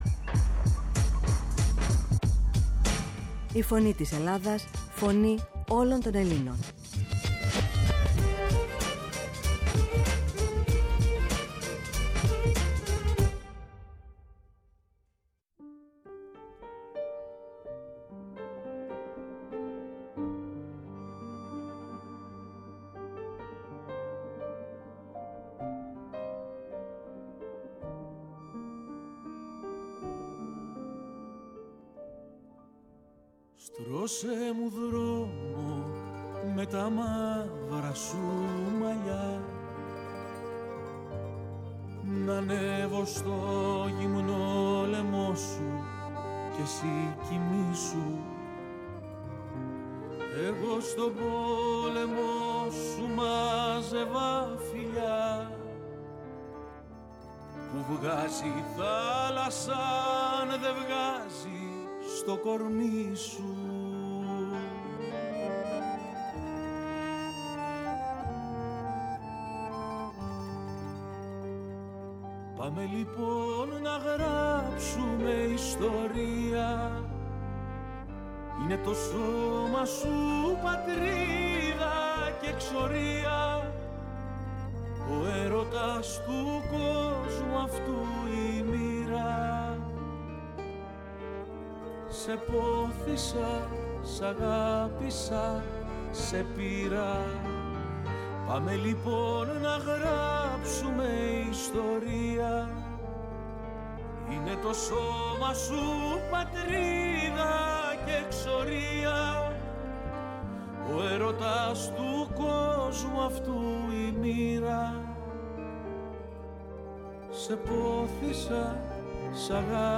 Στο στούντιο της “Φωνής της Ελλάδας” βρέθηκε καλεσμένη